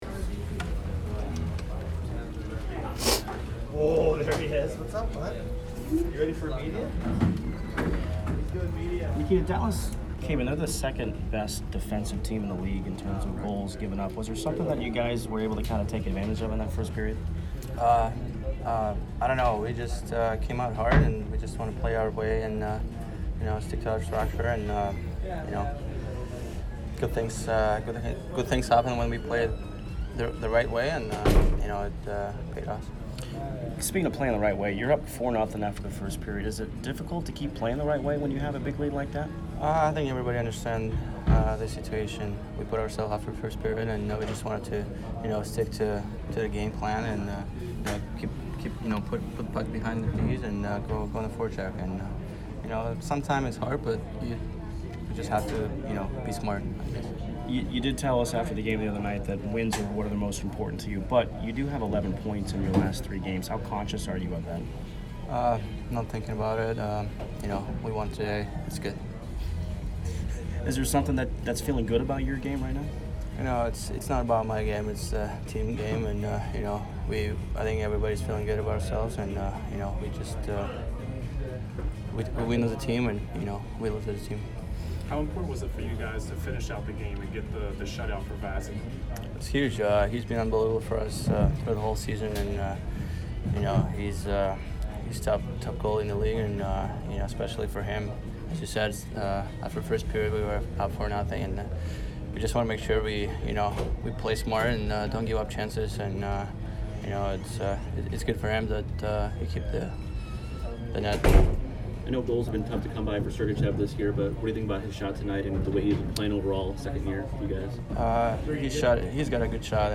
Nikita Kucherov post-game 2/14